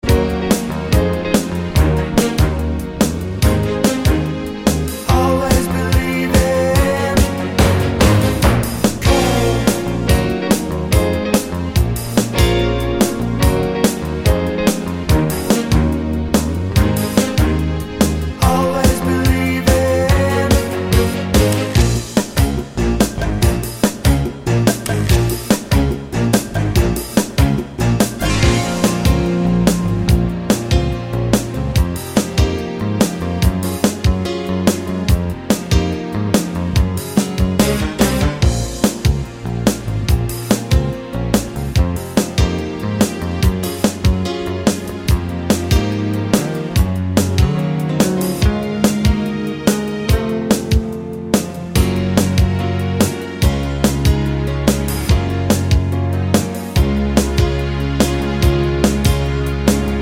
Twofers Medley Pop (1980s) 5:10 Buy £1.50